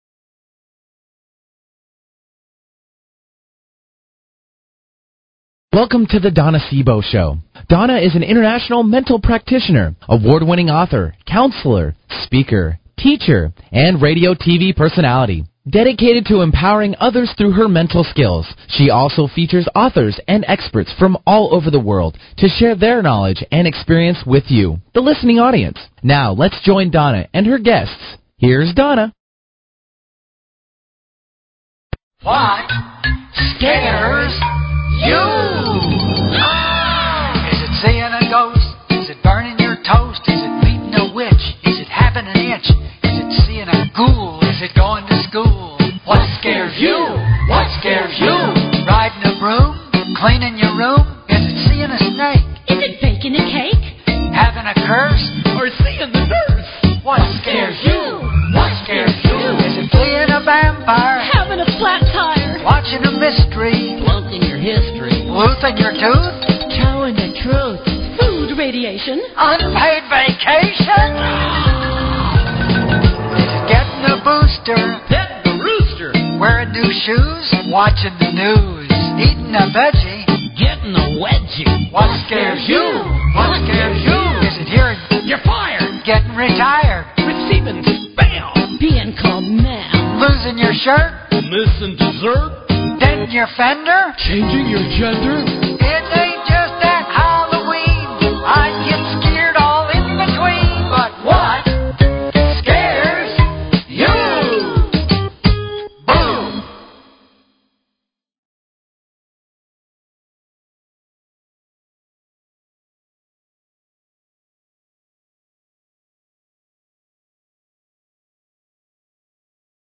Talk Show Episode, Audio Podcast
We'll be having an open mike show and you'll be able to call in any time to chat with me.